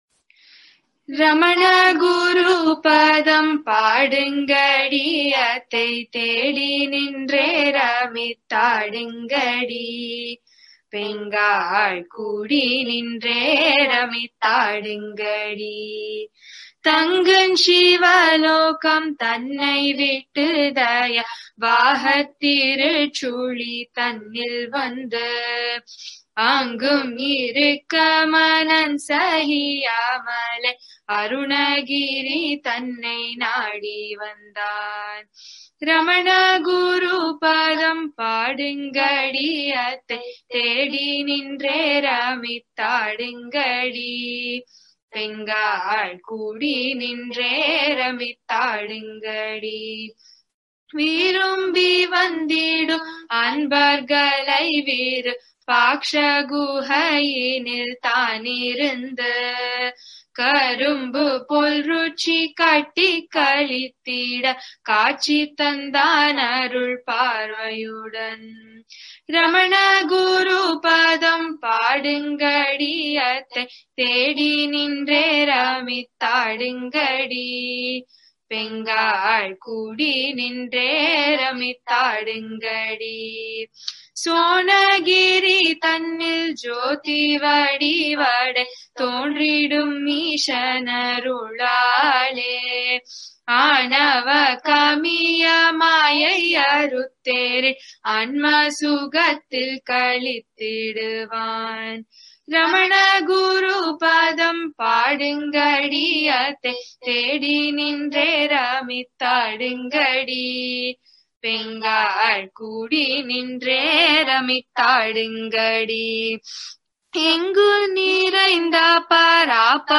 Kummi Song
Roundelay